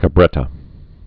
(kə-brĕtə)